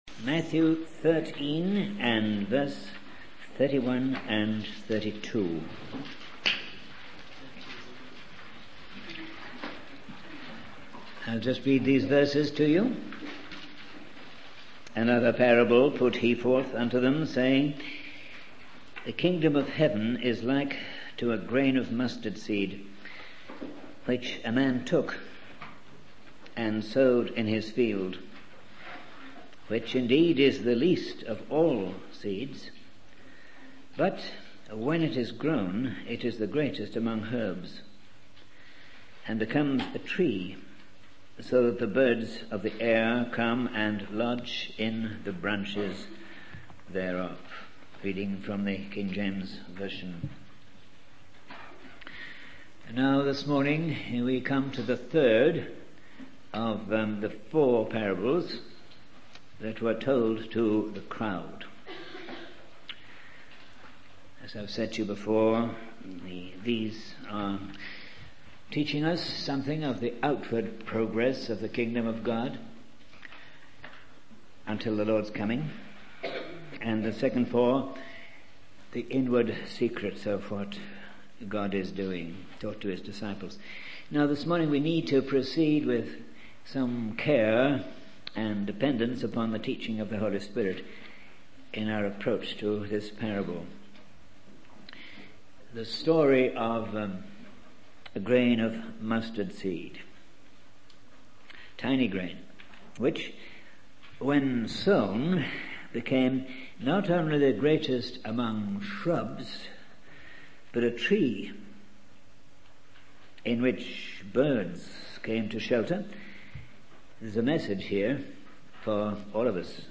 In this sermon, the speaker discusses the concept of faith as a grain of mustard seed.